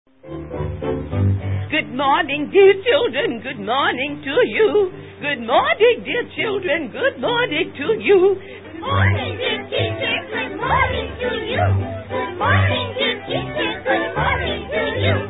in a 1935 cartoon